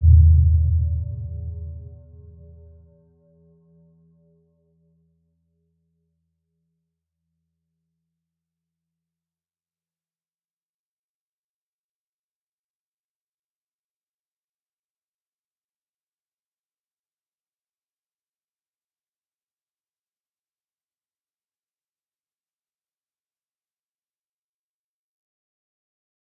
Round-Bell-E2-mf.wav